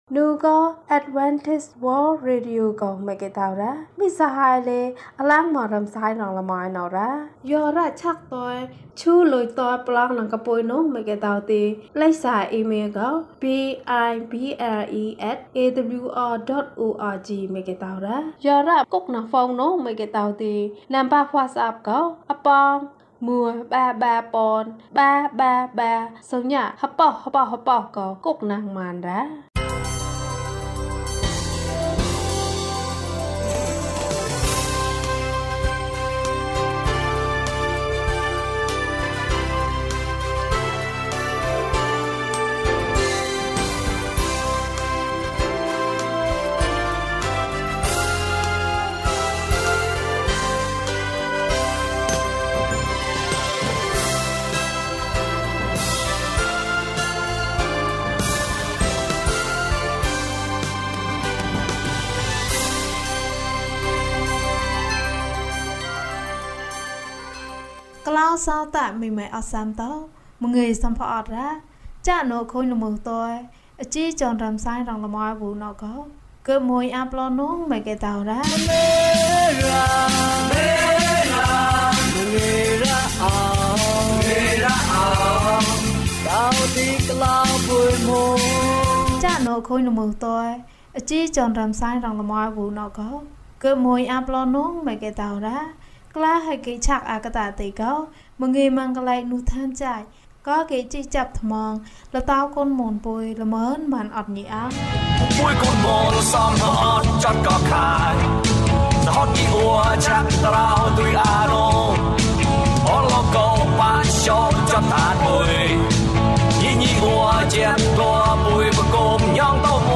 ငါဘုရားကို ချီးမွမ်းတယ်။ ကျန်းမာခြင်းအကြောင်းအရာ။ ဓမ္မသီချင်း။ တရားဒေသနာ။